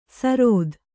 ♪ sarōd